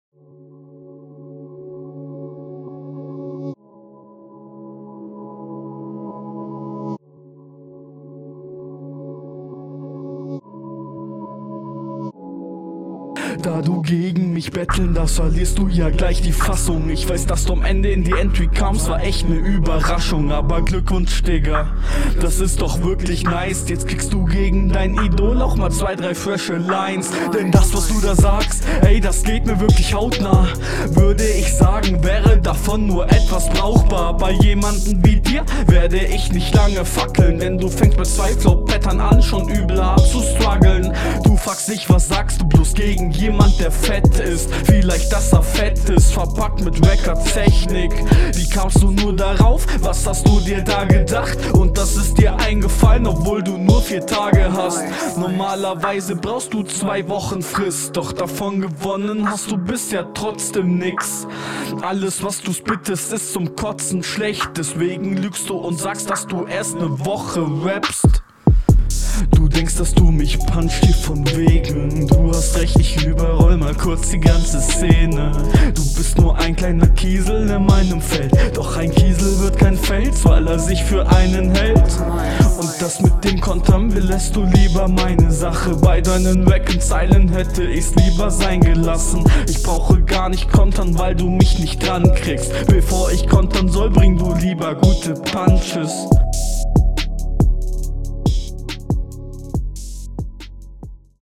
find bei dir das Soundbild etwas schwächer. außerdem wirfst du deinem Gegner vor, Patterns nicht …